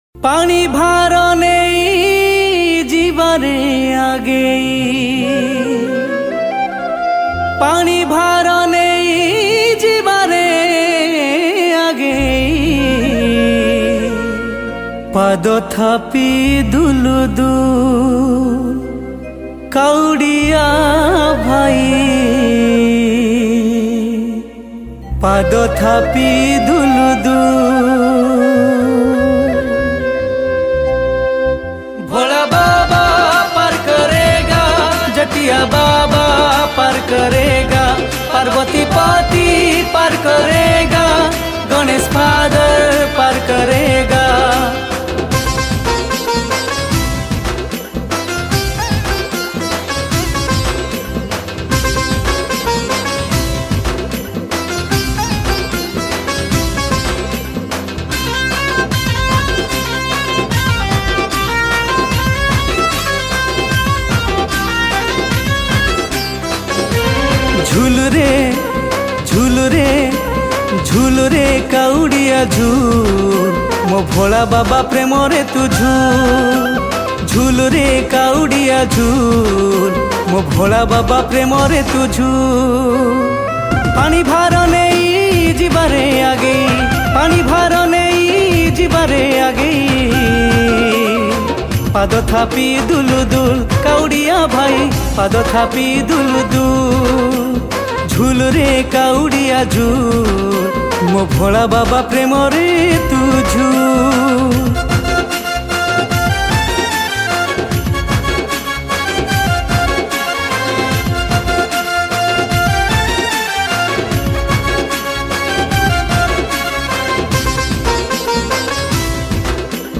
Odia Viral Bhajan Album